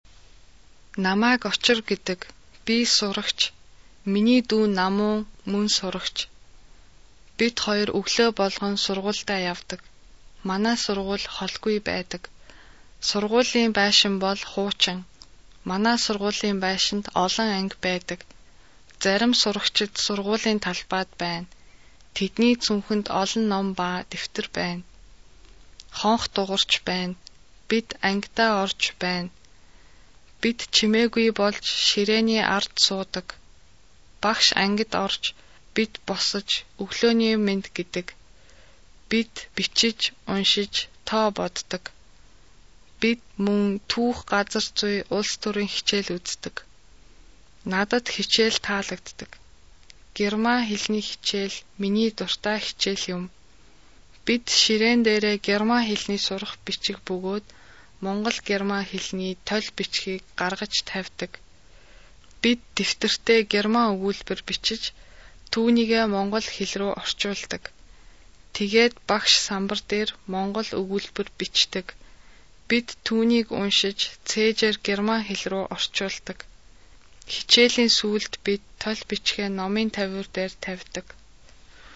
Dies ist das normale Sprechtempo!